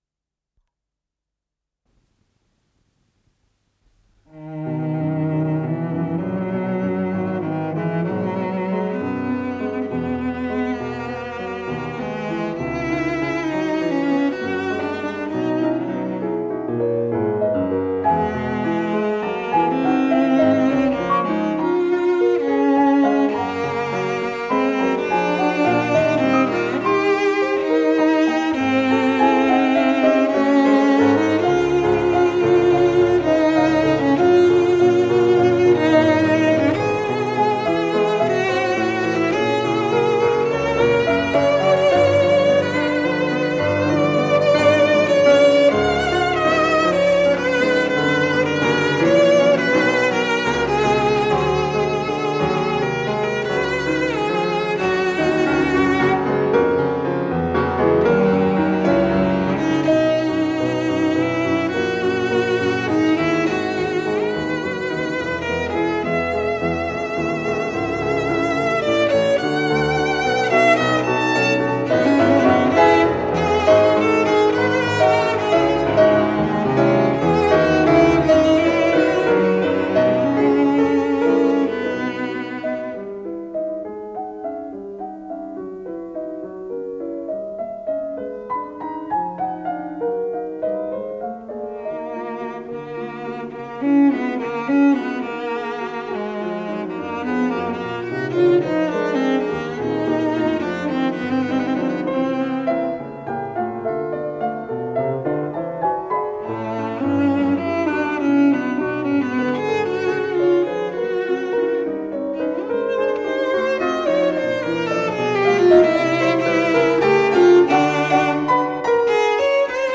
виолончель